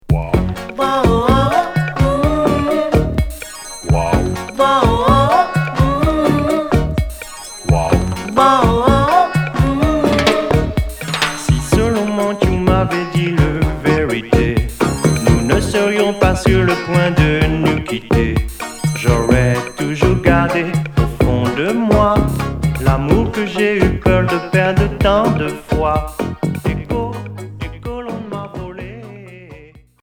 Reggae variété Deuxième 45t retour à l'accueil